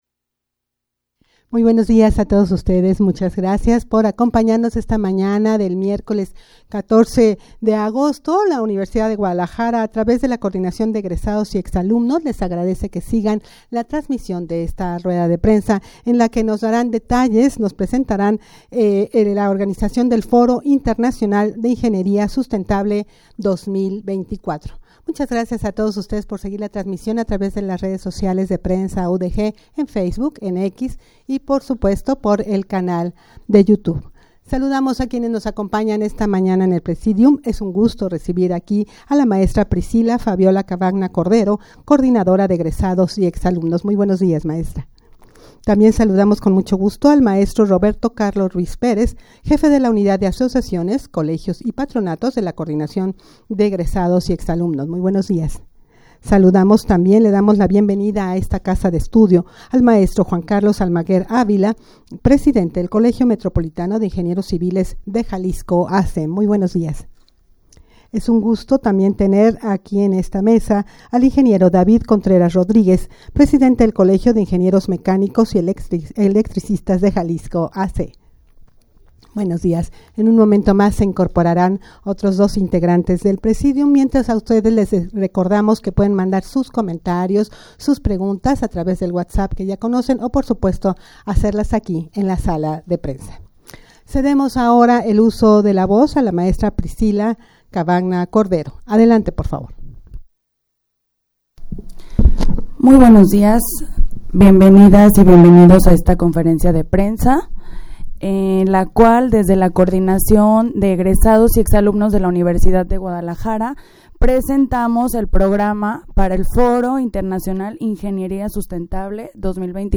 Audio de la Rueda de Prensa
rueda-de-prensa-presentacion-del-foro-internacional-de-ingenieria-sustentable.mp3